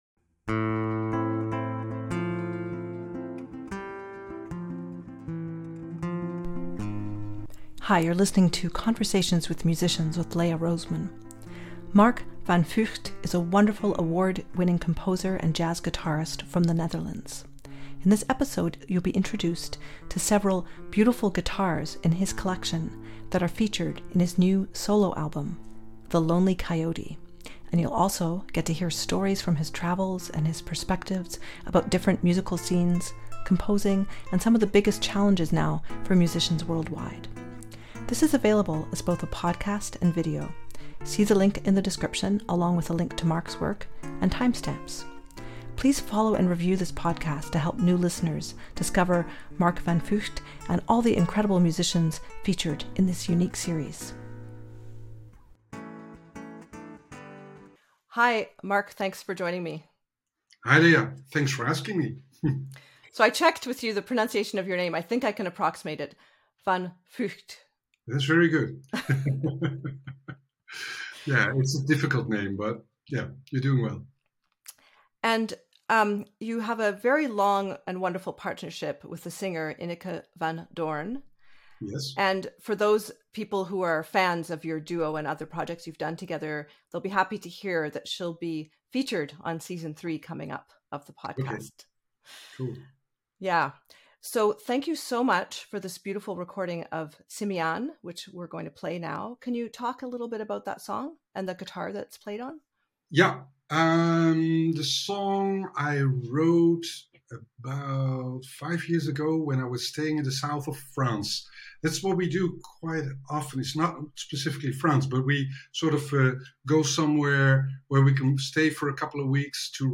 In this episode you’ll be introduced to several beautiful guitars in his collection that are featured in his new solo album, “The Lonely Coyote” and you’ll also get to hear stories from his travels and his perspectives about different musical scenes, composing, and some of the biggest challenges now for musicians worldwide.